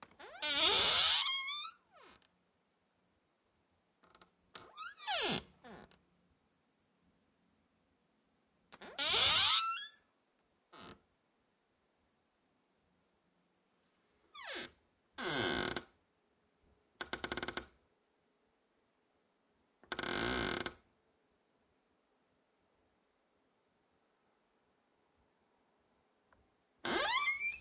门铰链发出吱吱的响声 O,C
描述：门的木铰链吱吱作响，打开关闭
Tag: 关闭 咯吱 铰链 开放 W OOD